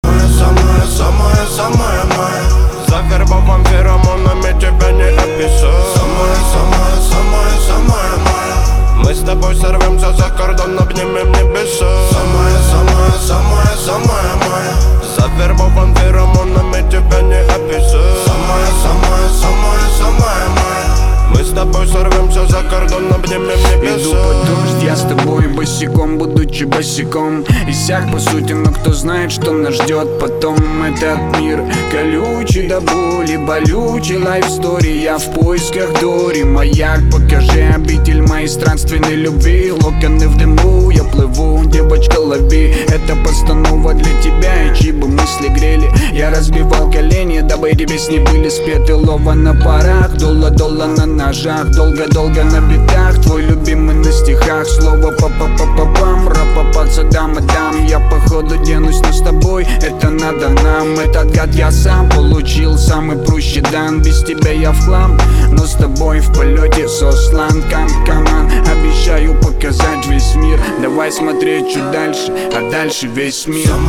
Русские рингтоны